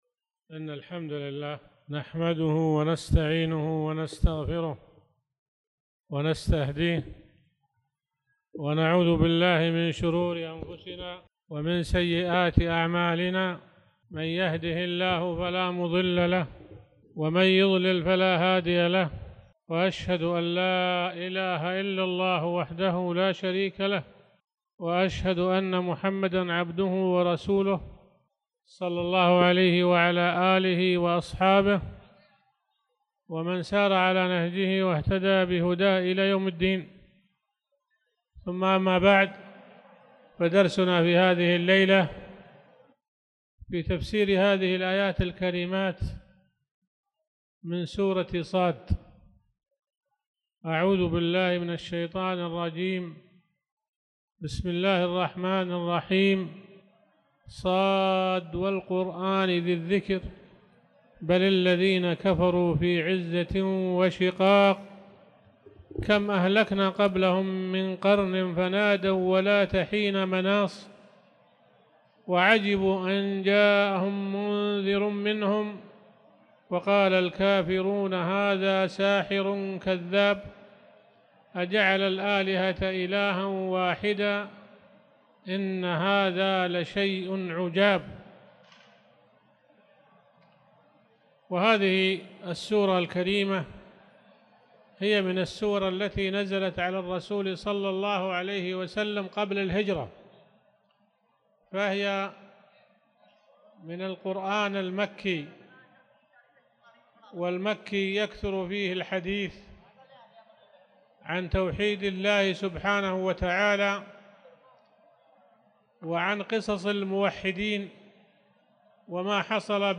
تاريخ النشر ١٢ ذو القعدة ١٤٣٧ هـ المكان: المسجد الحرام الشيخ